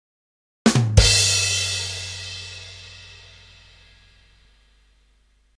Comedy Punchline Rimshot Sound Effect
Comedy-Punchline-Rimshot-Sound-Effect.mp3